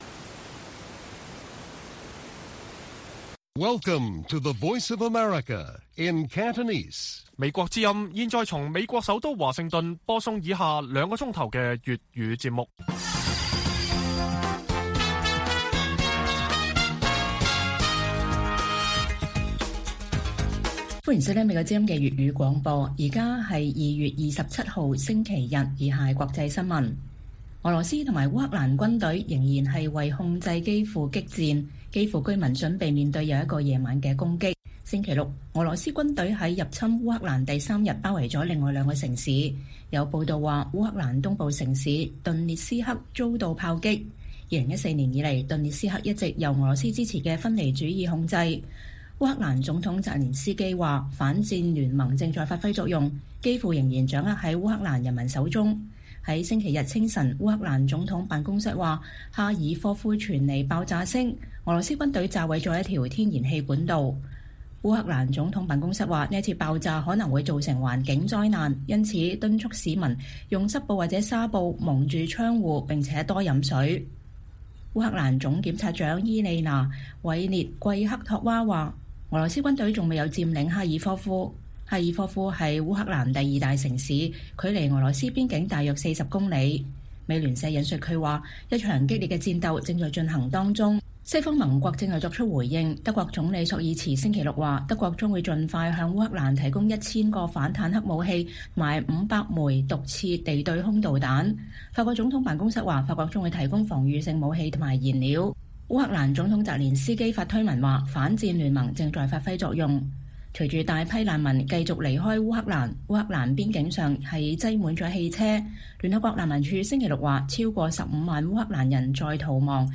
粵語新聞 晚上9-10點：澤連斯基：基輔仍掌握在烏克蘭人民手中